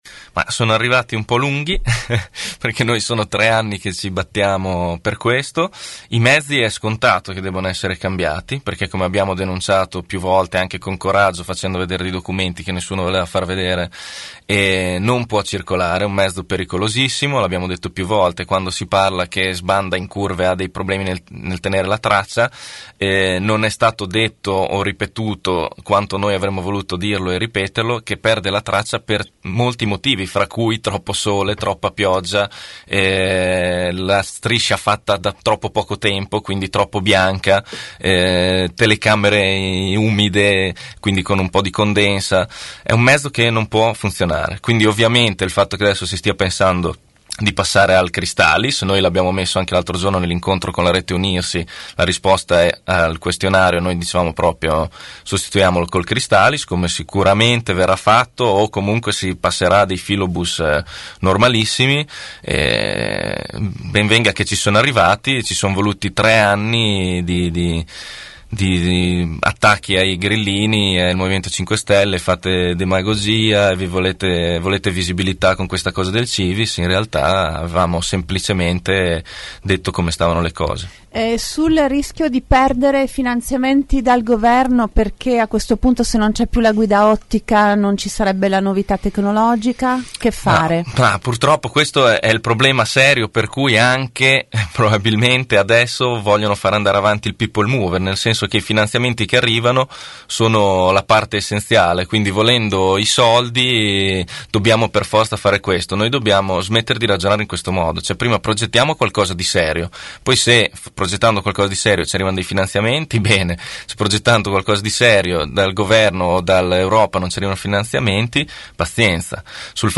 ospite dei nostri studi